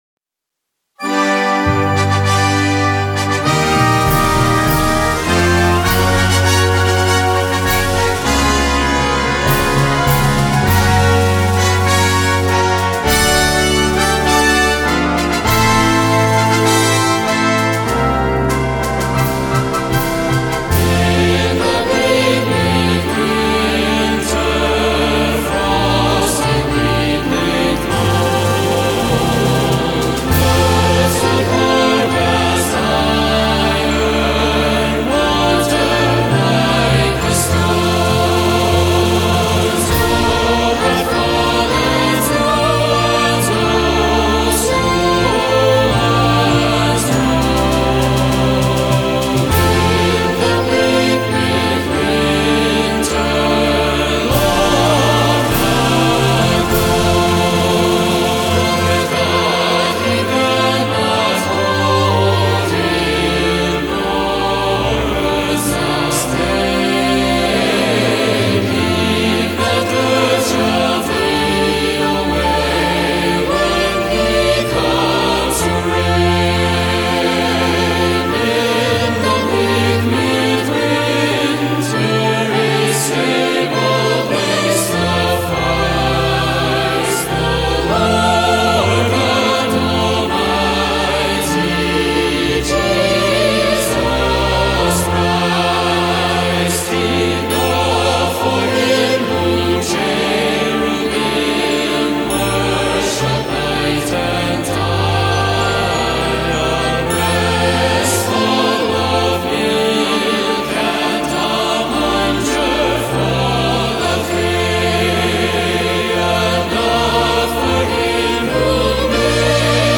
The choir parts are optional.
InstrumentationPiccolo
Euphonium
Timpani
Glockenspiel
Tubular Bells
Choir (Optional)
Concert Wind Band